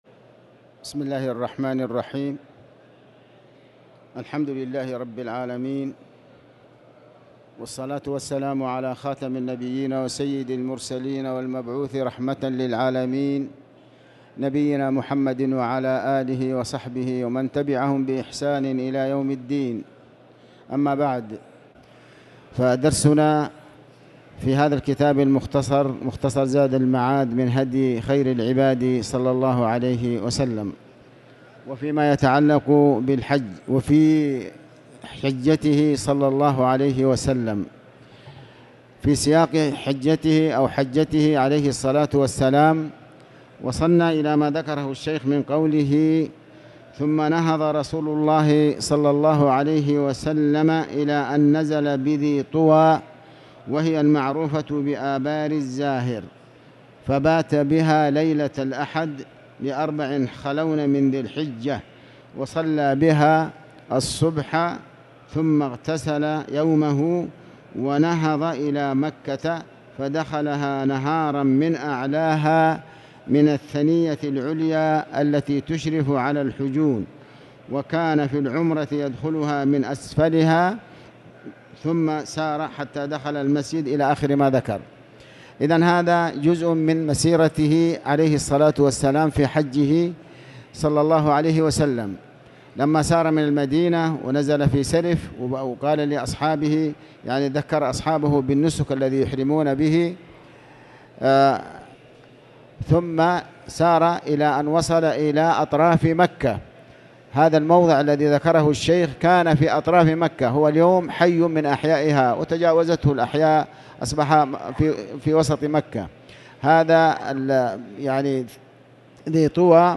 تاريخ النشر ٢٨ ذو القعدة ١٤٤٠ هـ المكان: المسجد الحرام الشيخ: علي بن عباس الحكمي علي بن عباس الحكمي الحج وهديه صلى الله عليه وسلم فيه